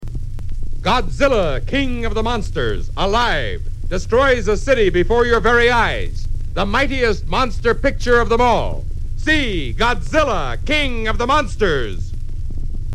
Oddly enough, Godzilla’s characteristic roar is not heard in these spots.
So, here they are…as listeners would have heard them back in 1956!
Godzilla King of the Monsters Radio Spots for 12, 50, and 100 seconds versions.